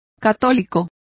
Complete with pronunciation of the translation of catholic.